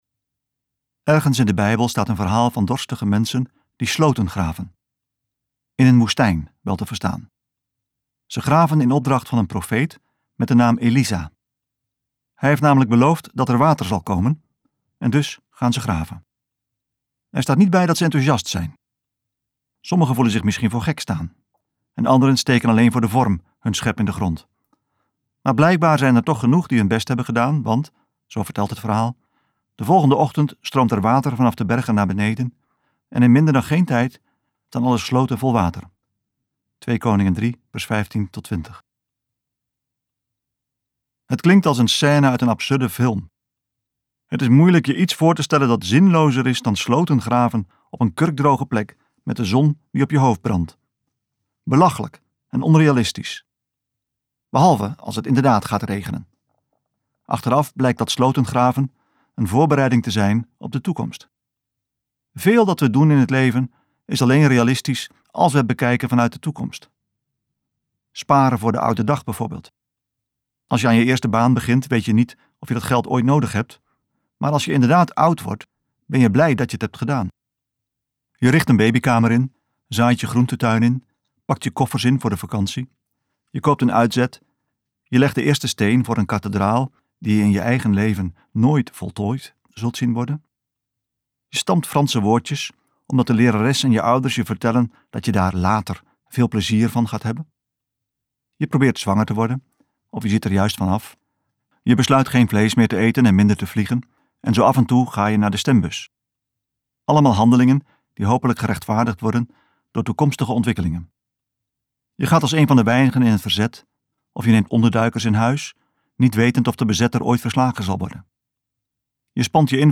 KokBoekencentrum | De weg van vrede luisterboek